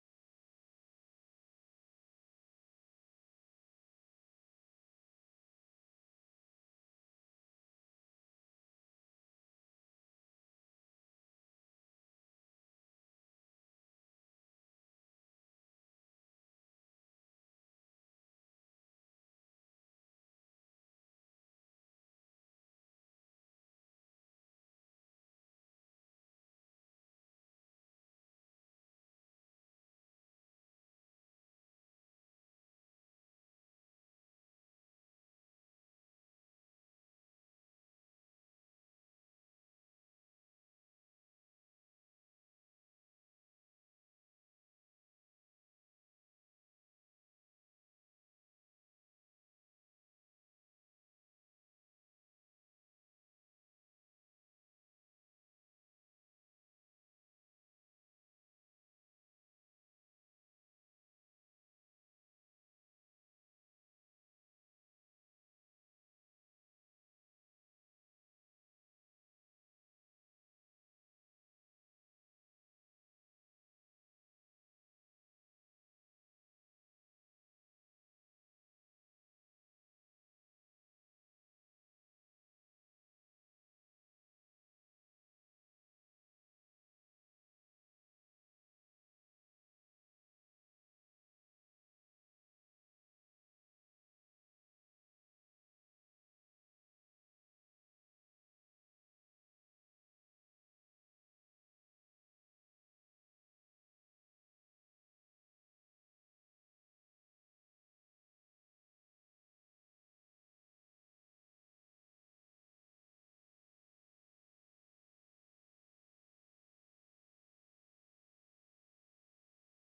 VIRTUAL LEARNING LAB ARCHIVE
Vital Village Network  Simultaneous Spanish interpretation service s will be available / La interpretación simultánea en español estará disponible.